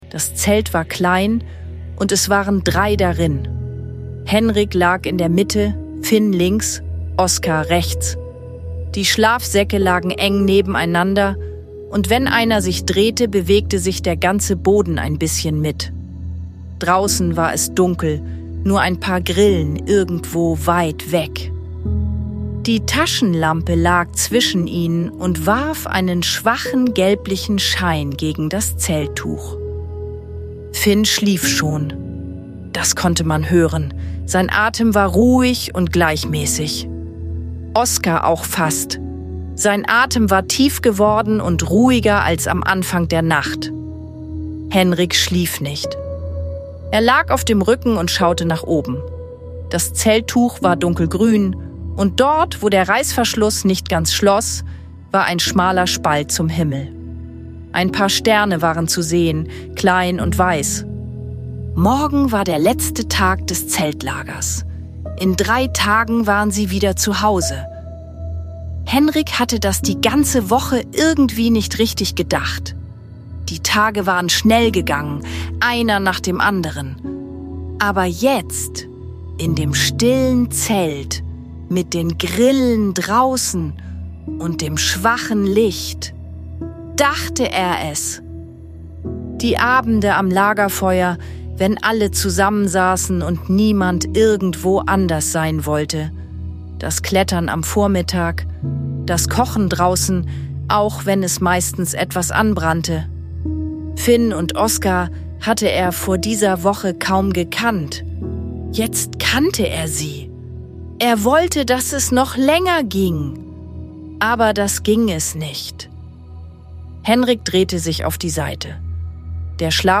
Eine ruhige Gute-Nacht-Geschichte über Freundschaft, Zusammenhalt und Gedanken, die leise weiterwachsen dürfen.